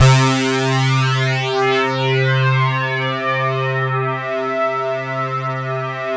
SYN FAT   01.wav